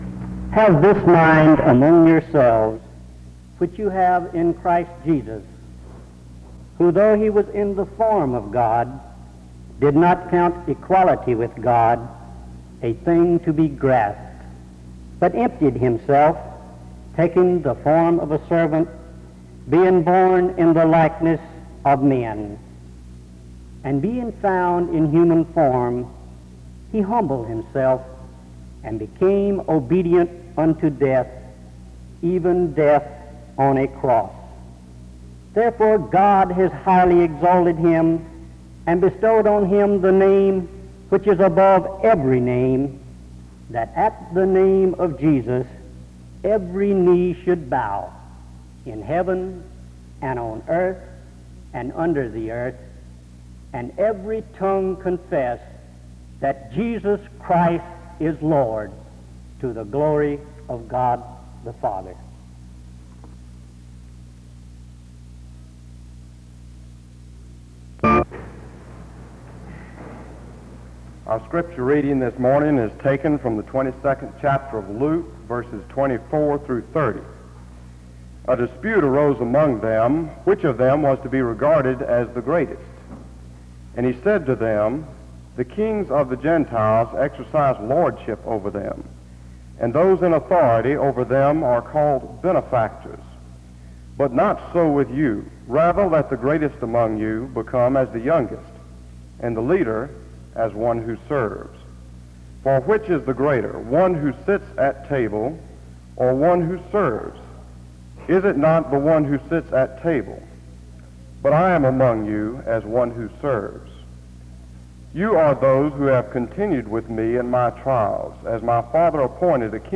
The service begins with a scripture reading (0:00-1:03).
The service continues with a period of singing (2:18-4:01). A prayer is offered (4:02-4:50).